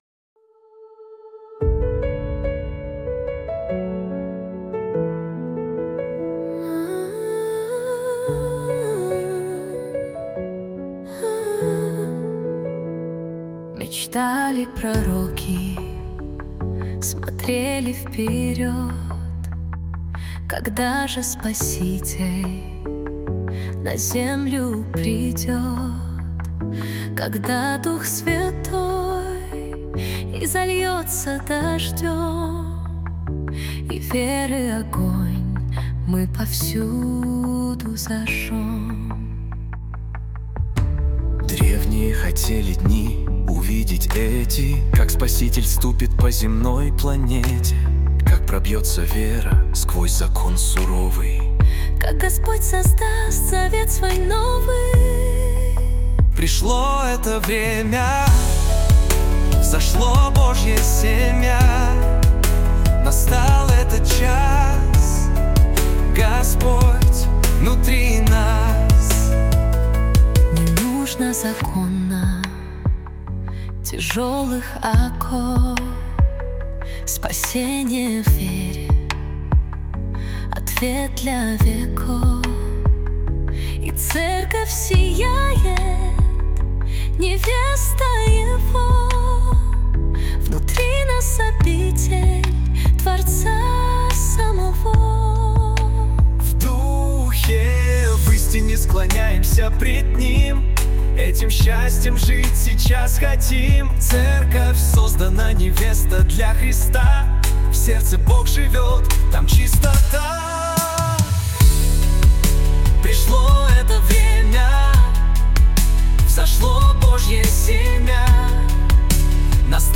песня ai
182 просмотра 804 прослушивания 65 скачиваний BPM: 72